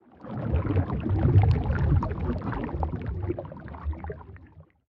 Sfx_creature_glowwhale_swim_slow_04.ogg